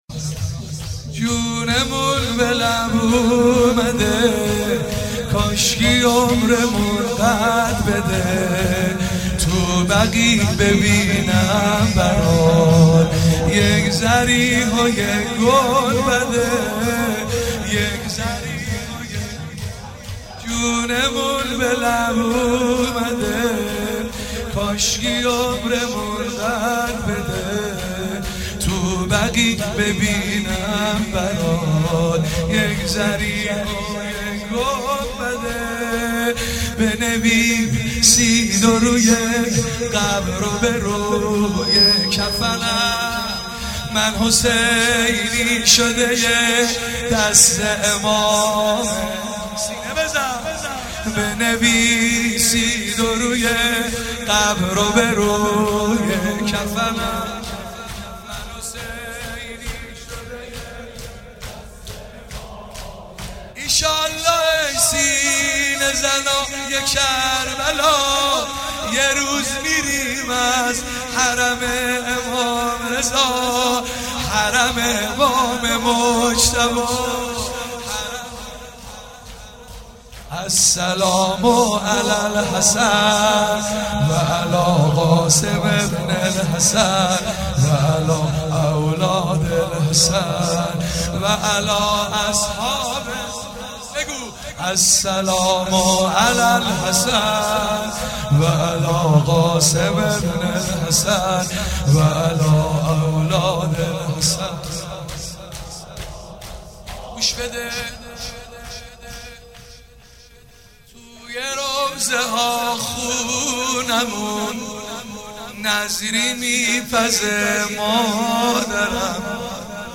09 marasem 7 safar94 heiate alamdar mashhad alreza.mp3